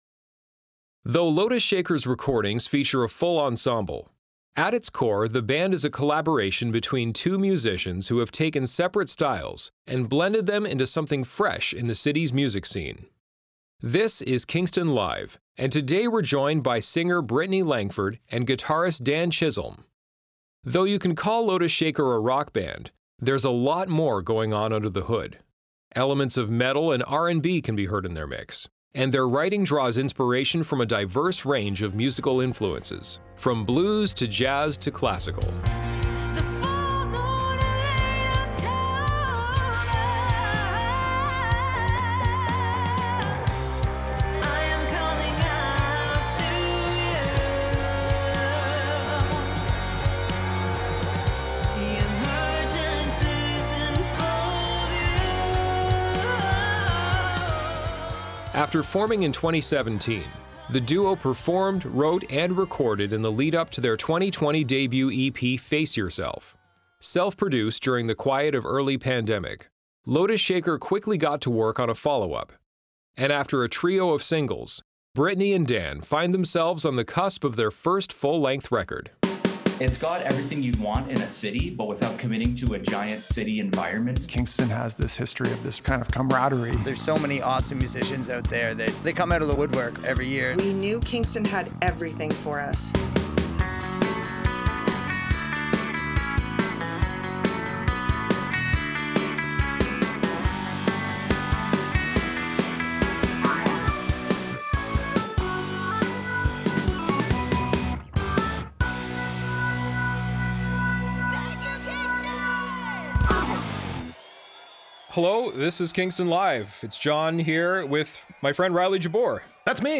A chat with Lotus Shaker about their new album